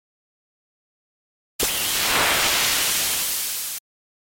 Play Battle Woosh - SoundBoardGuy
Play, download and share Battle Woosh original sound button!!!!
battle-woosh.mp3